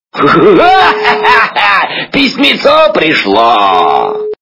» Звуки » звуки для СМС » Звонок для СМС - Ого-го, письмецо пришло!
При прослушивании Звонок для СМС - Ого-го, письмецо пришло! качество понижено и присутствуют гудки.